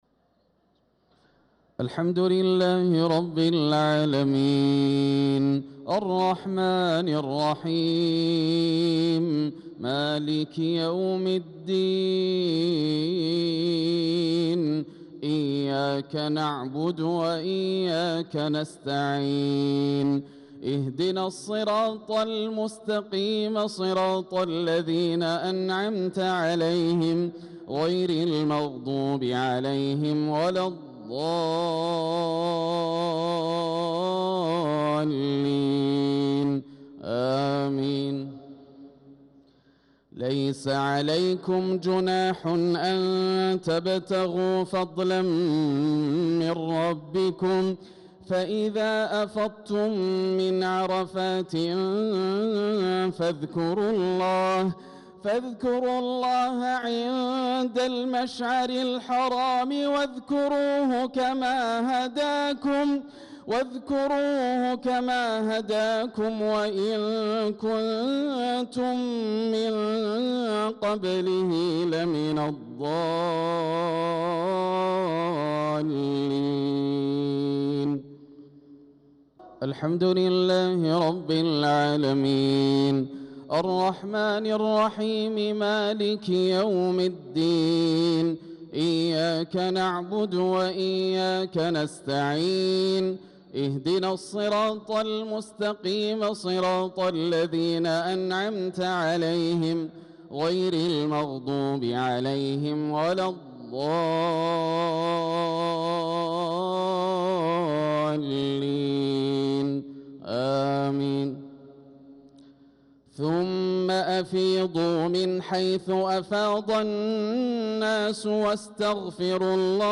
صلاة المغرب للقارئ ياسر الدوسري 10 ذو الحجة 1445 هـ
تِلَاوَات الْحَرَمَيْن .